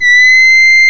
VOICE C6 S.wav